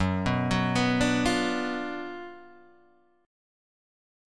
F# Major 7th Suspended 4th Sharp 5th